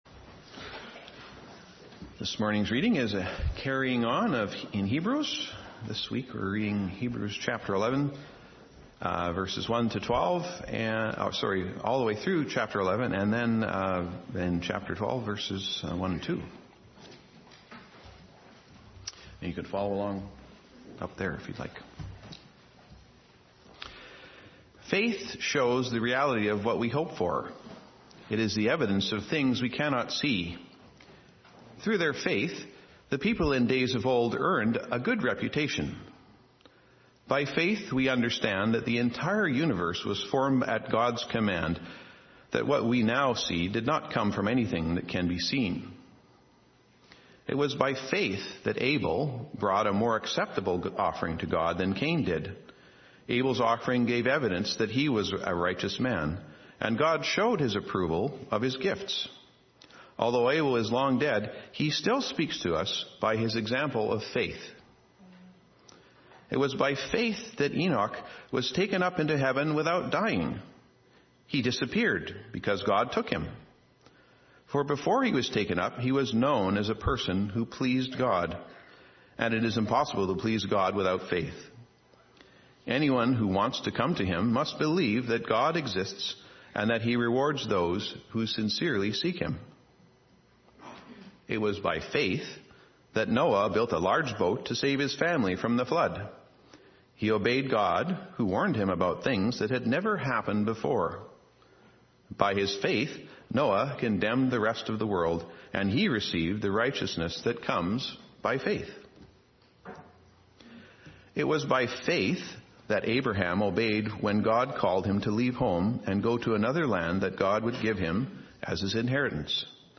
Sermons | Olivet Baptist Church